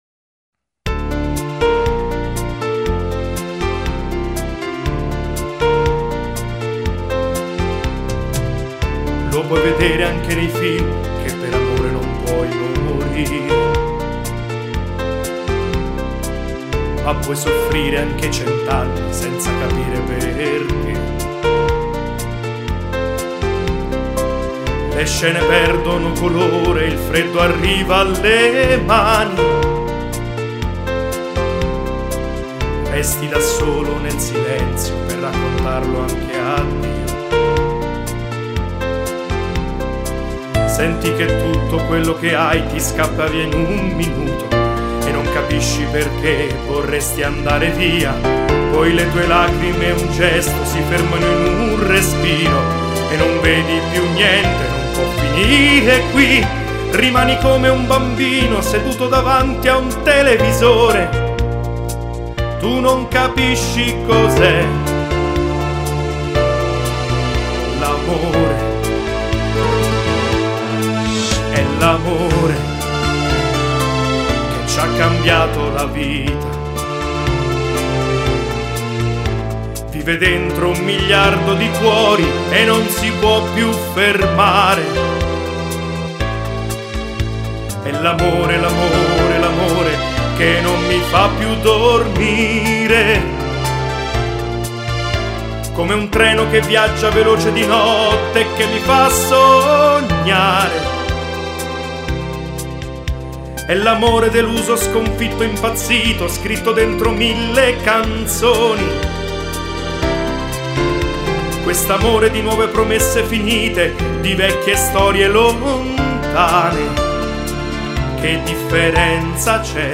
Commedia musicale